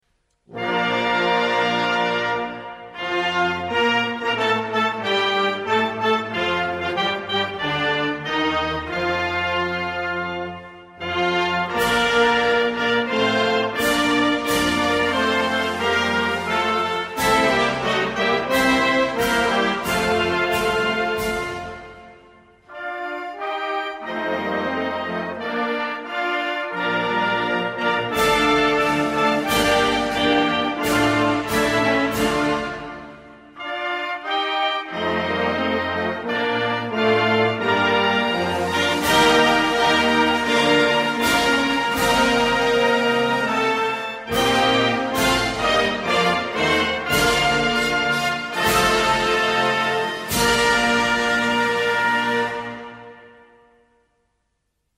Slovenian_anthem.mp3